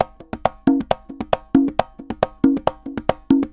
Congas_Merengue 136-1.wav